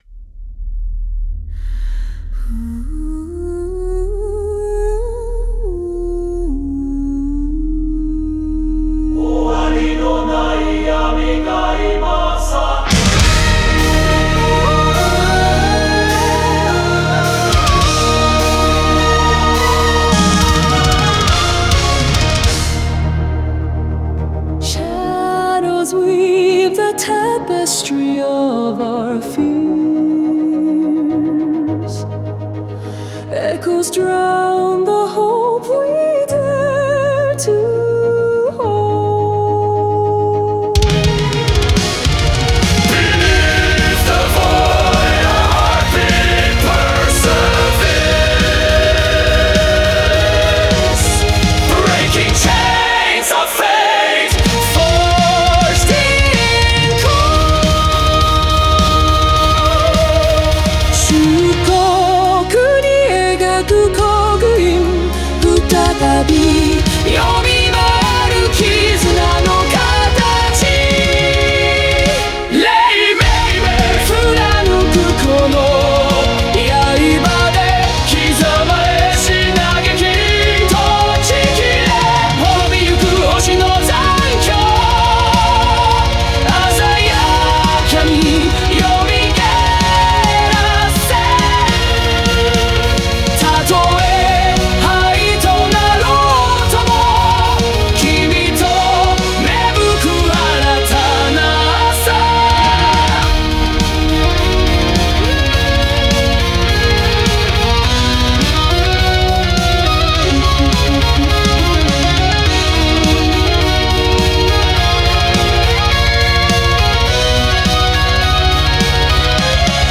Symphonic Metal
Dynamic shifts and dramatic pauses heighten suspense.